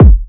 Carti (Kick).wav